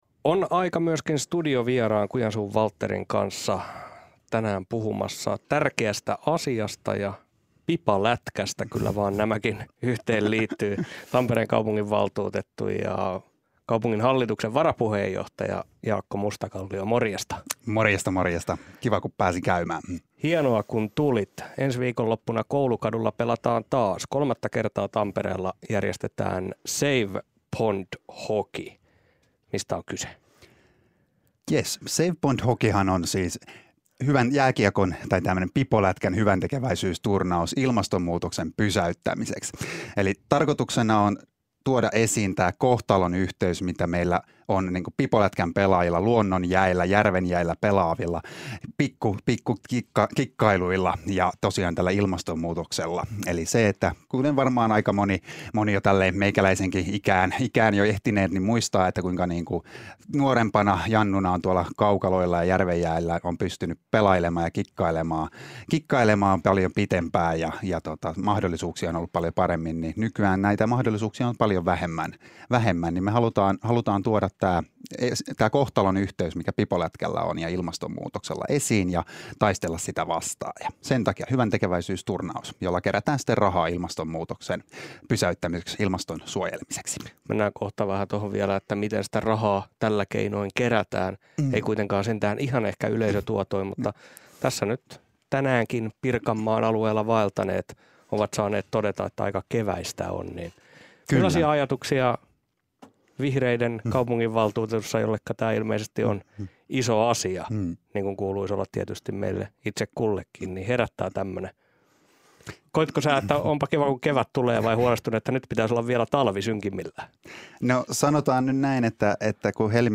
Päivän vieraana Tampereen kaupunginvaltuuttettu ja Save Pond Hockey -turnauksen Jaakko Mustakallio.